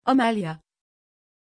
Aussprache von Amélya
pronunciation-amélya-tr.mp3